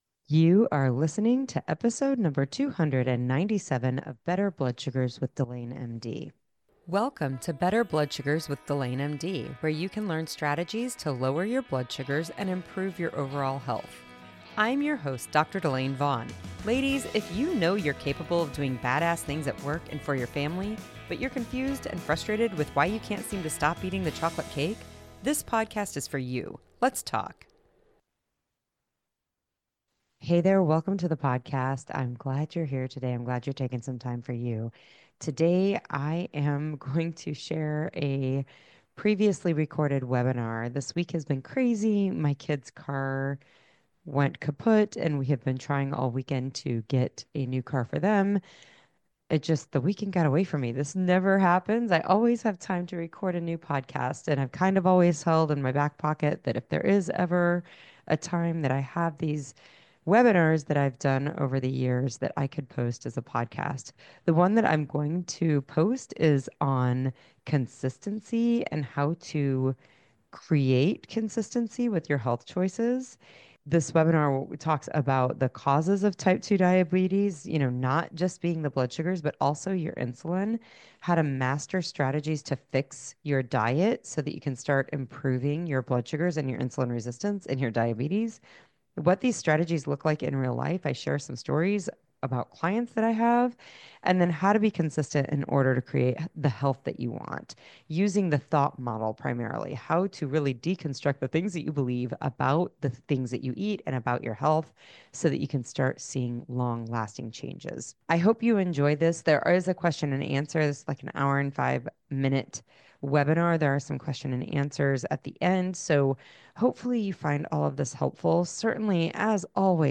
This is a webinar I did in 2024 about mastering consistency. I cover insulin resistance as the cause of your diabetes, how to master strategies to fix your diabetes, client stories, and how to create consistency.